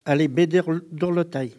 Collectif patois et dariolage
Locution